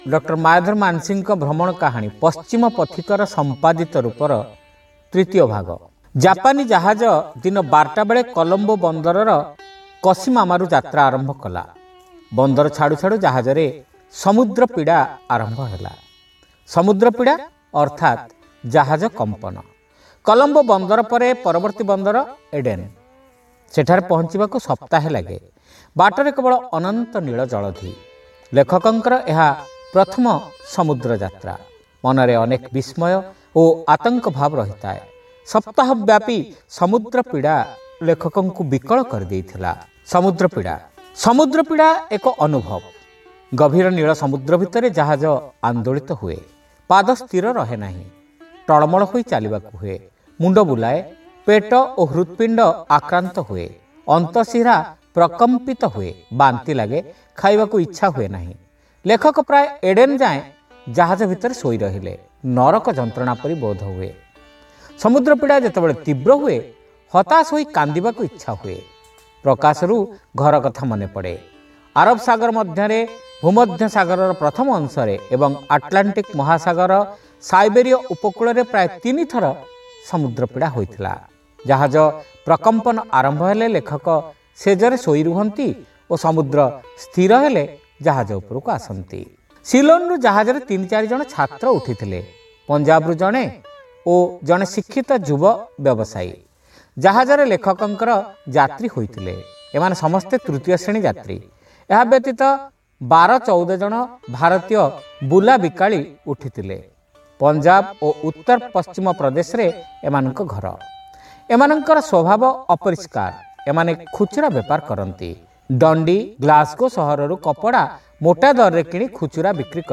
Audio Story : Paschima Pathika ra Sampadita Rupa (Part-3)